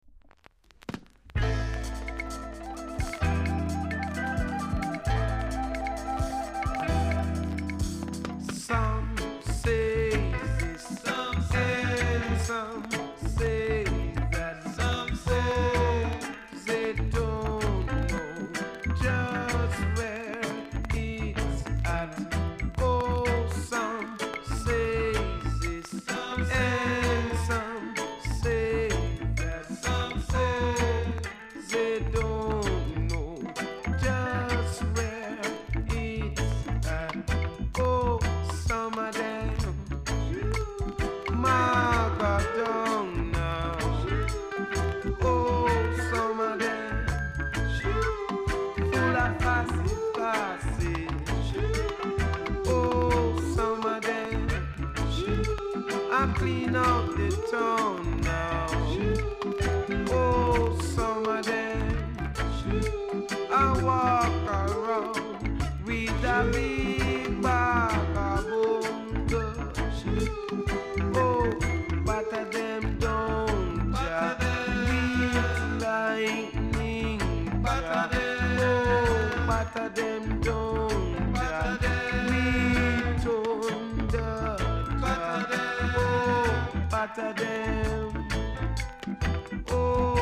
※チリ、パチノイズが所々あります。